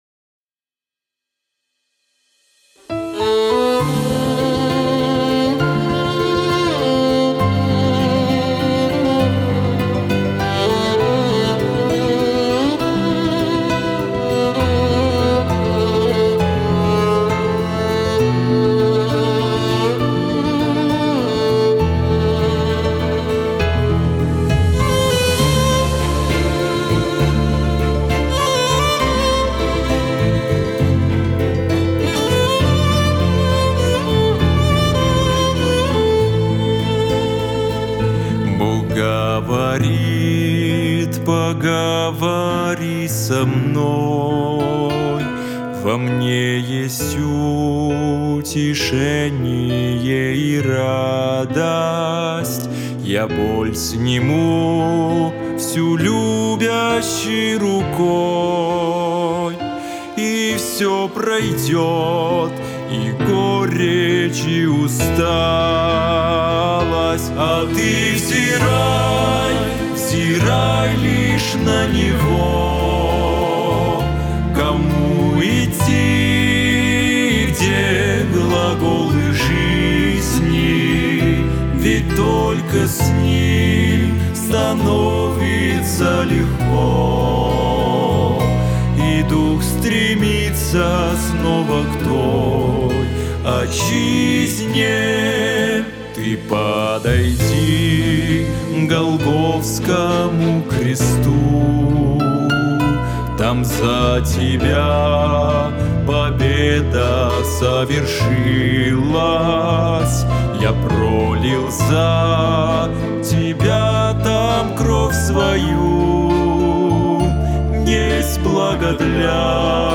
3018 просмотров 1635 прослушиваний 196 скачиваний BPM: 67